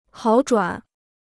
好转 (hǎo zhuǎn): to improve; to take a turn for the better.